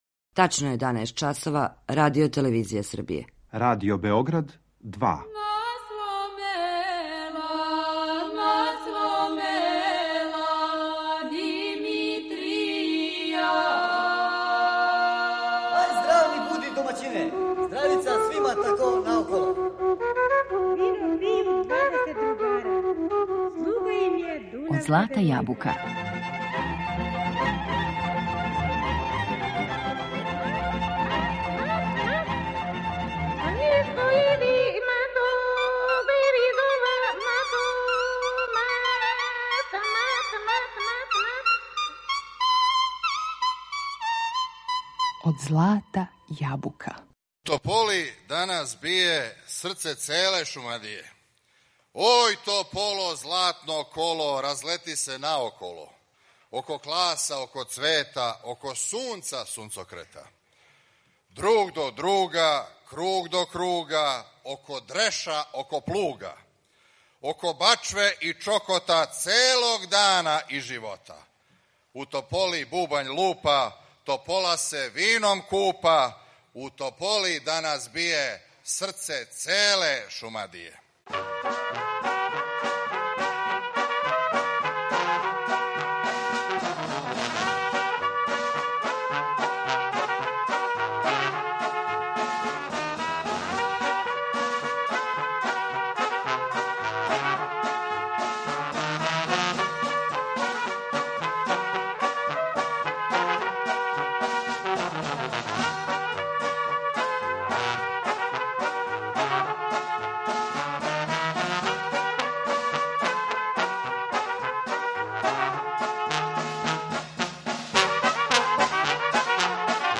Сваке године, у другој недељи октобра, под окриљем Опленца, одржава се Сабор изворног народног стваралаштва, у оквиру манифестације „Опленачка берба”.
Кроз програм Сабора, кроз презентацију традиционалне песме, народне игре и свирке, тежи се ка очувању изворног народног стваралаштва и културног наслеђа. У данашњој емисији Од злата јабука пред нама је музичко-документарни снимак који смо забележили ове године у Тополи.